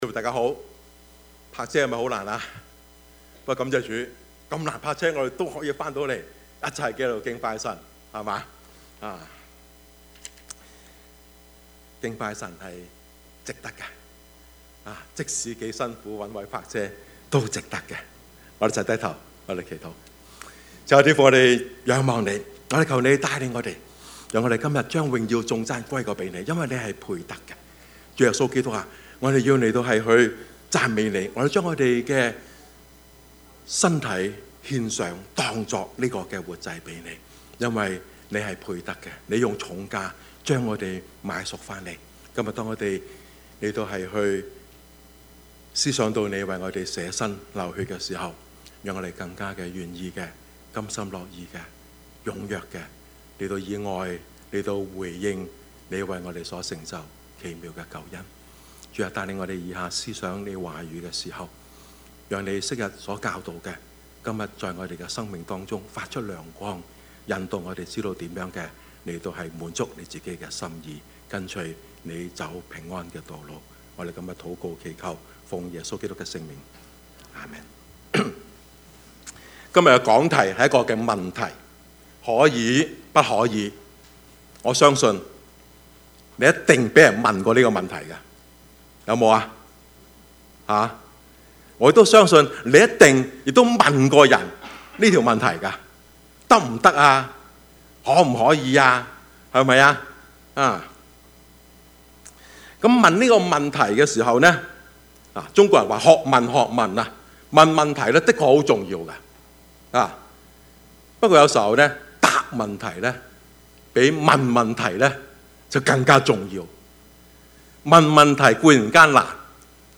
Service Type: 主日崇拜
Topics: 主日證道 « 我是誰？